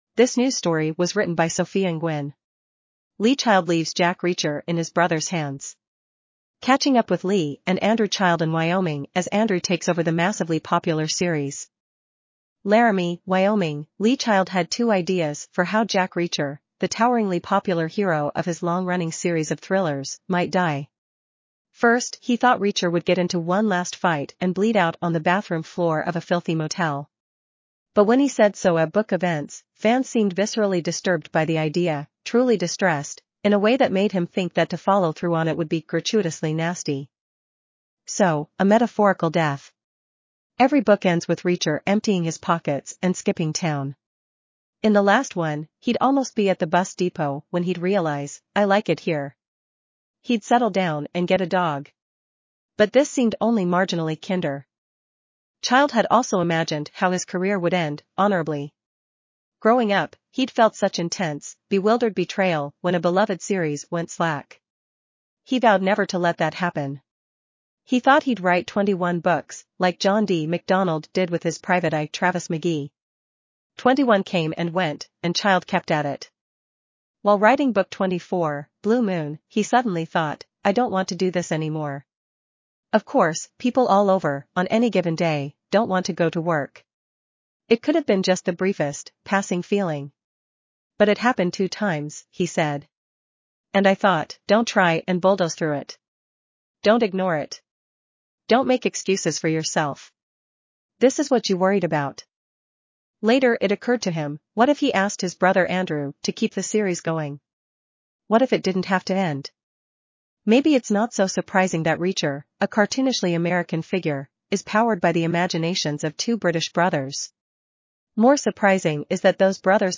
azure_en-US_en-US-JennyNeural_standard_audio.mp3